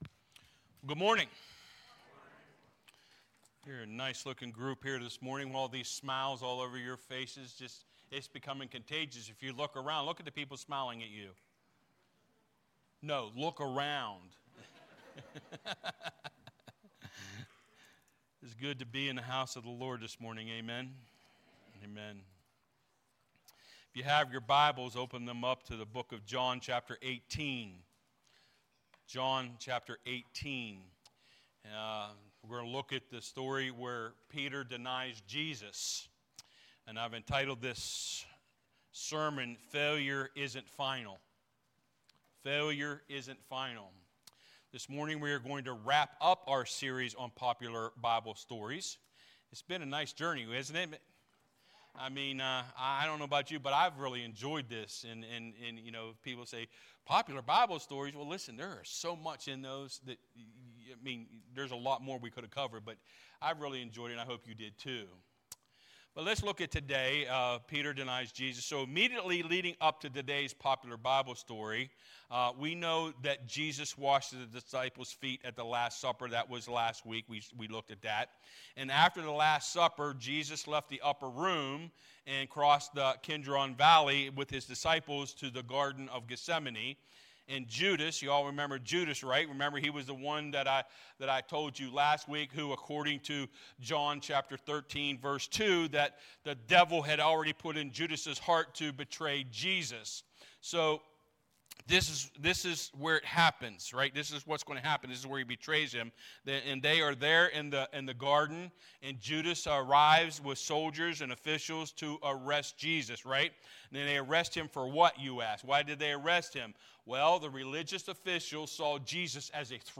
(Due to technical issues, the beginning worship songs were not recorded, so the recording starts with the sermon. We apologize for the inconvenience.)